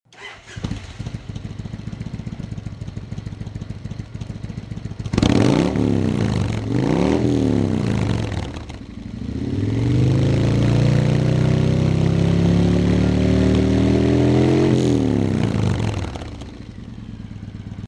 To hear V&H Baggers modified with longer baffles and fiberglass packing.  As sample at left this audio was recorded differently than those showing waveforms and can't be compared to them.